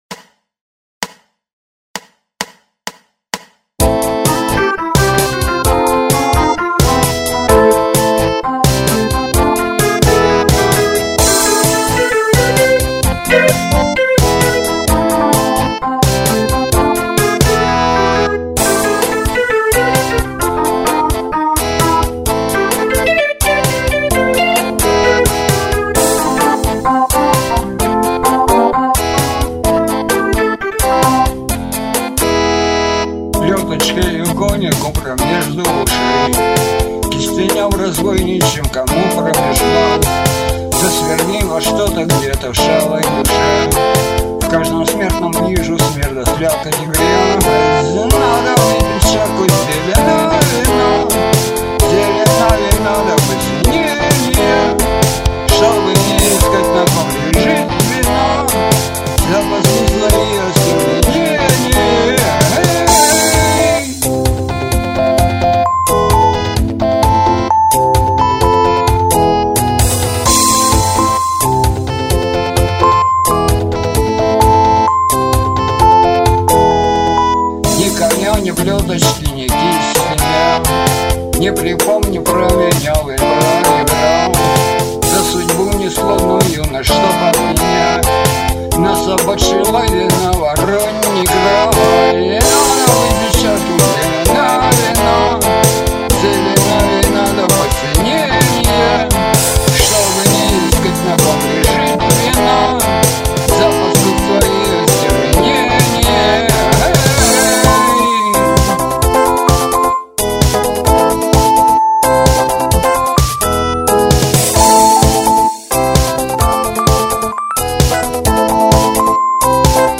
Музыкальный хостинг: /Бардрок